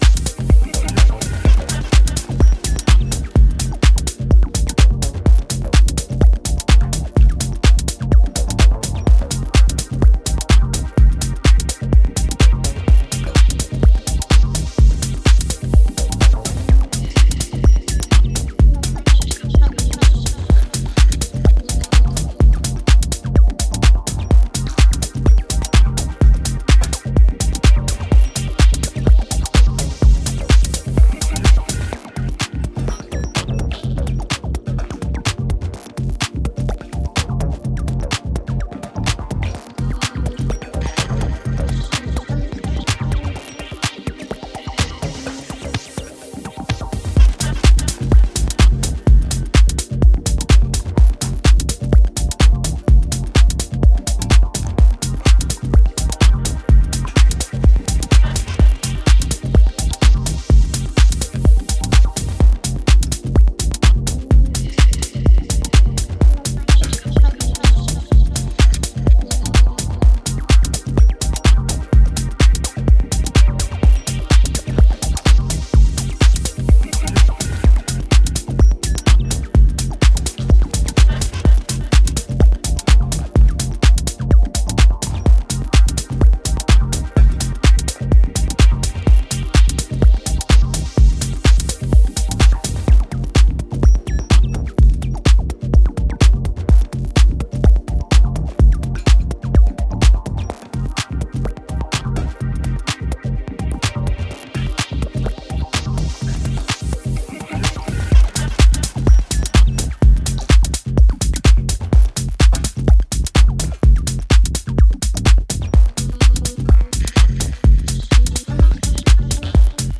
stomping and dreamy sounds
This is an absolute weapon for dancefloors!